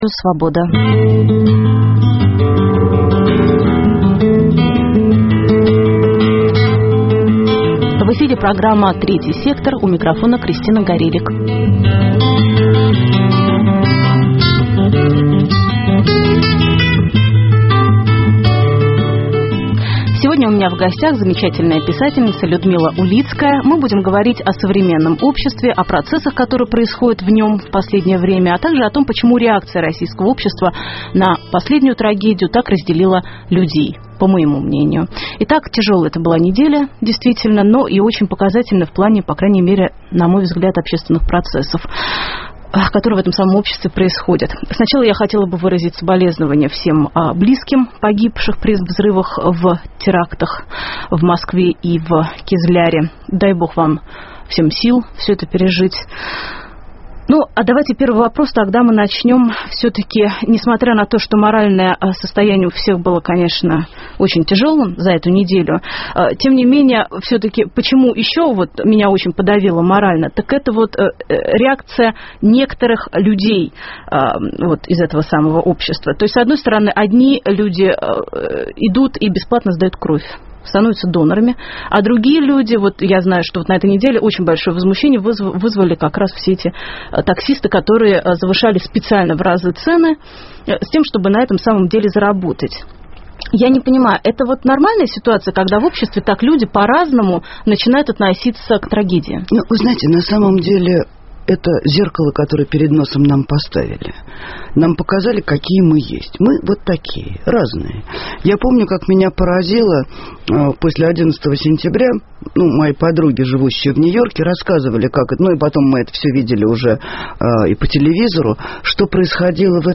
В гостях писатель Людмила Улицкая. Говорим о современном обществе, о процессах, которые происходят в нем в последнее время и о том, почему реакция российского общества на трагедию так разделила людей.